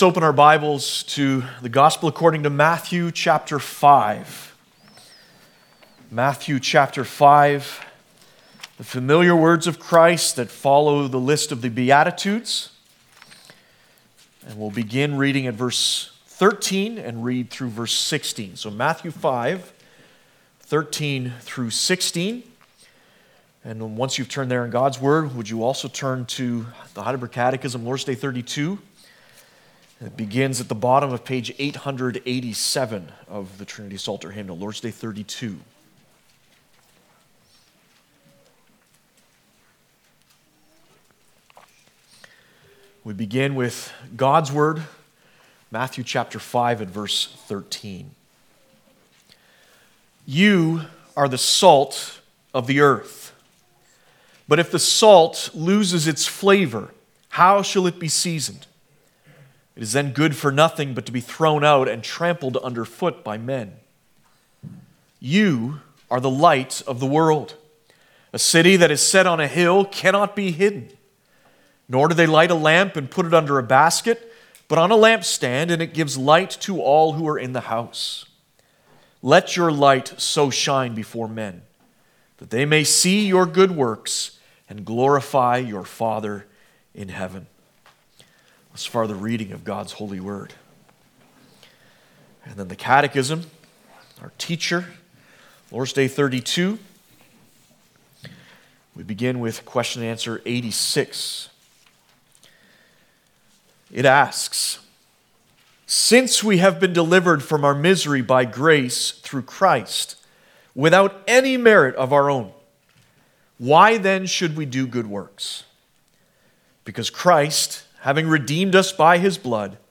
Passage: Matthew 5:13-16 Service Type: Sunday Afternoon « The LORD Gives His Maidservant Peace Abide in Me